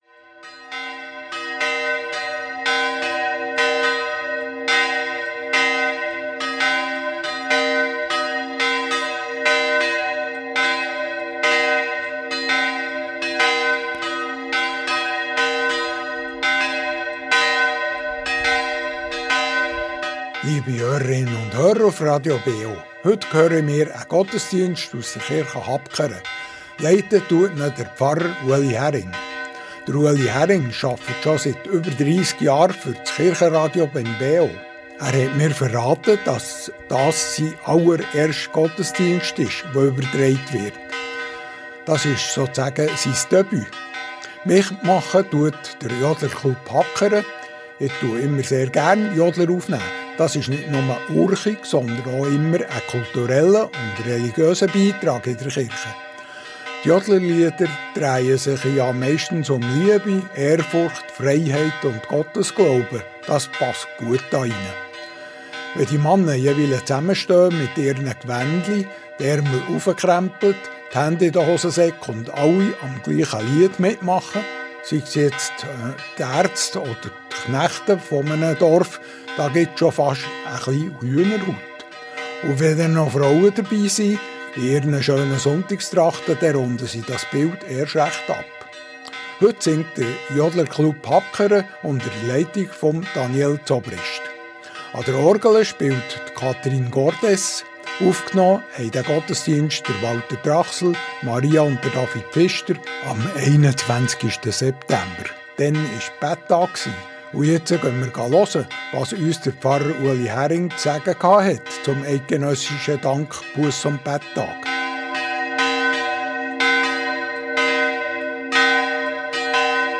Reformierte Kirche Habkern ~ Gottesdienst auf Radio BeO Podcast